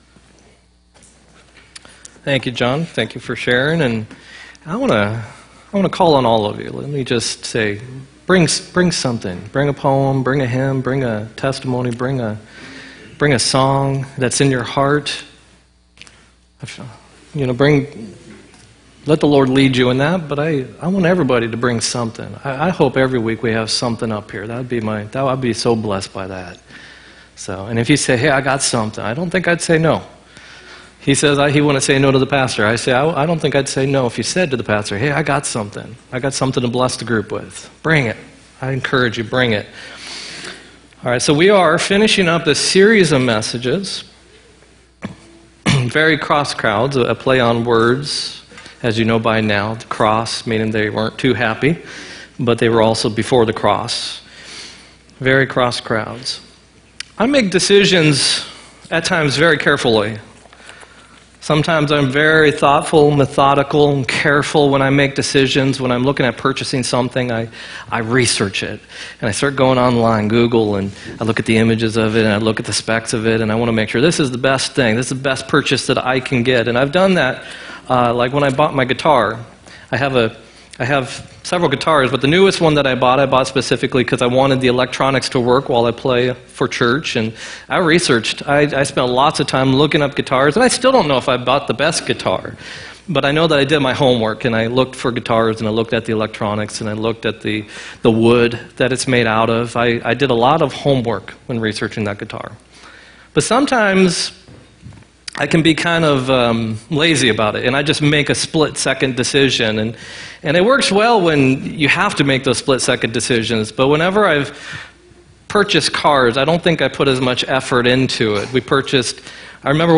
3-24-18 sermon